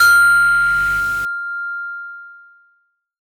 SOUTHSIDE_percussion_it_goes_bing.wav